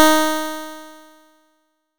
nes_harp_Ds4.wav